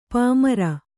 ♪ pāmara